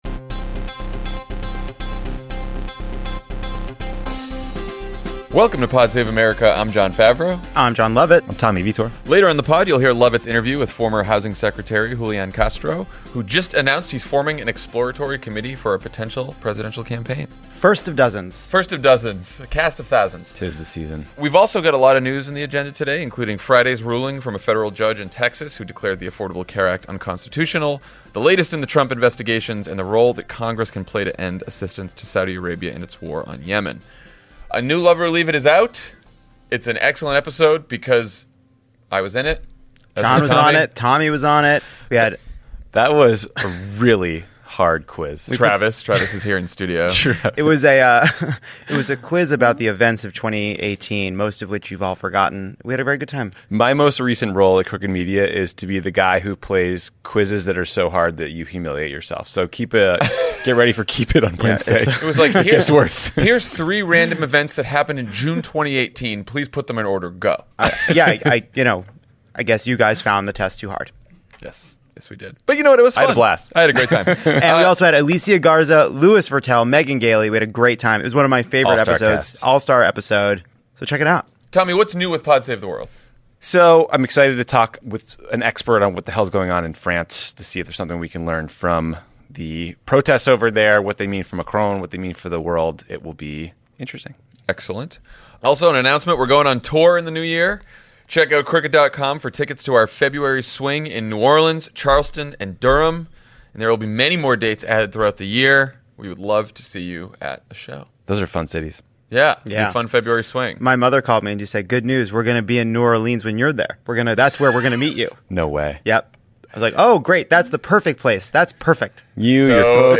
A Texas judge rules in favor of Republicans trying to dismantle Obamacare, Trump and Rudy flail against a legal onslaught, and a bipartisan coalition tries to end U.S. support for the Saudi-led war in Yemen. Then former Housing and Urban Development Secretary Julian Castro talks to Jon Lovett about his plans to run for president in 2020.